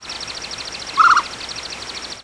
Northern Flicker Colaptes auratus
Flight call description A soft, sweet "pdrrr", not unlike the whirring of wings. In short flights occasionally gives a loud, emphatic "skeew".